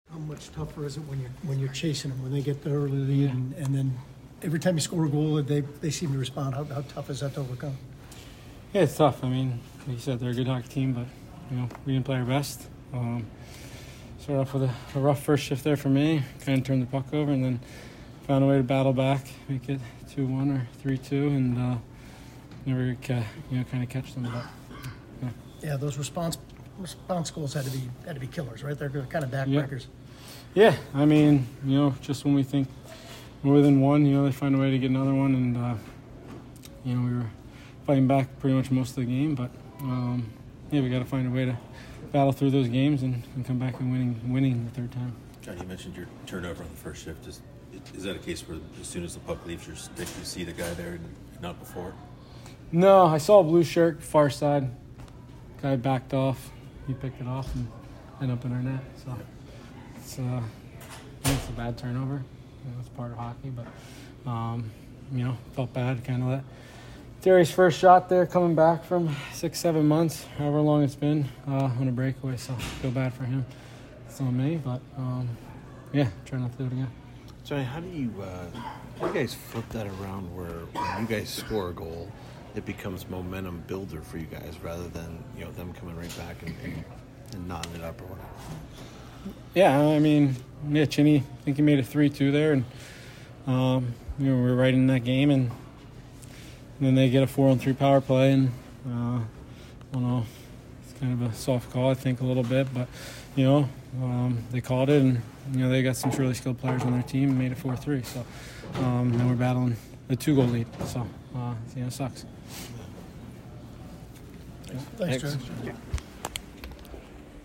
BLUE JACKETS POST-GAME AUDIO INTERVIEWS